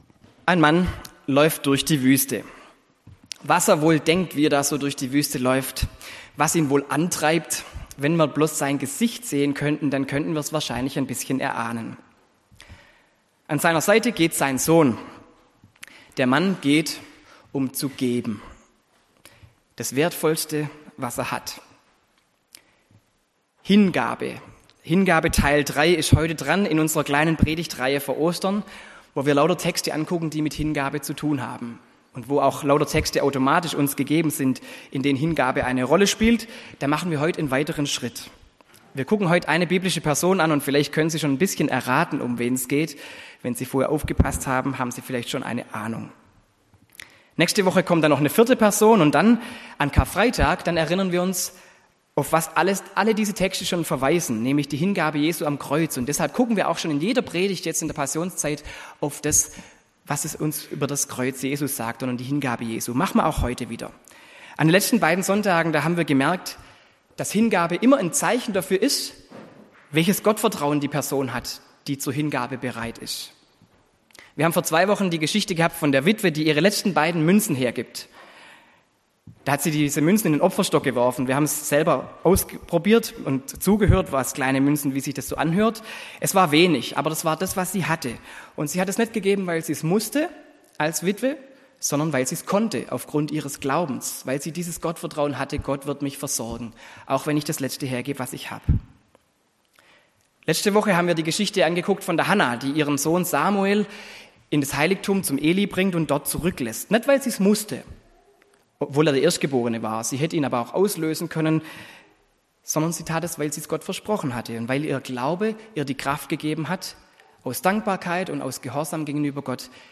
Dritte Predigt zur Themenreihe "Hingabe"